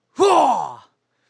su_cheer1.wav